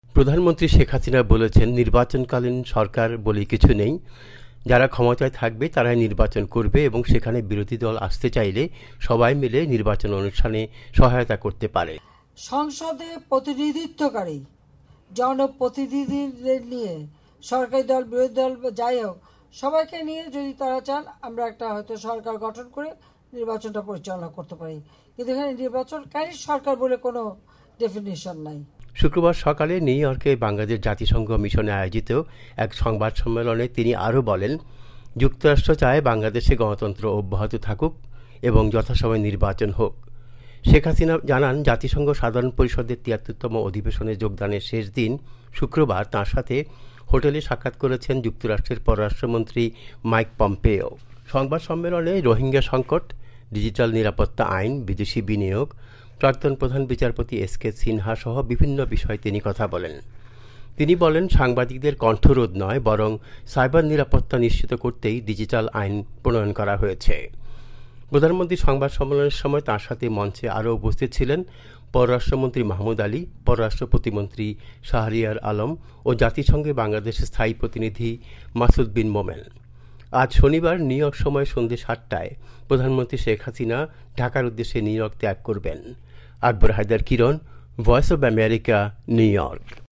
নিউ ইয়রক থেকে আমাদের প্রতিনিধি